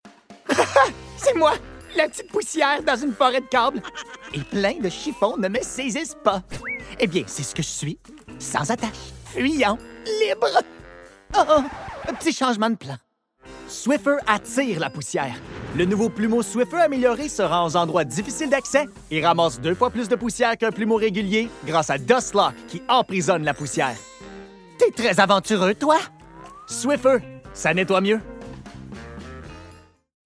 Commercial (Swiffer) - FR